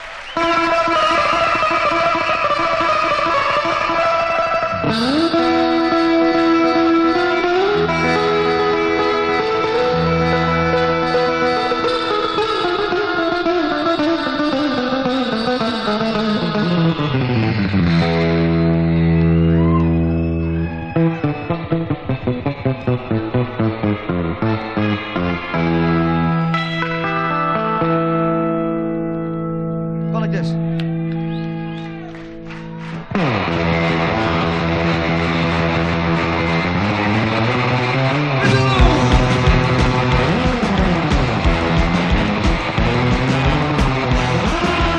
終始ゴキゲンなロックンロールを展開した痛快なライブ盤。
Rock, Surf　USA　12inchレコード　33rpm　Stereo